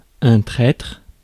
Ääntäminen
France (Paris): IPA: [ɛ̃ tʁɛtʁ]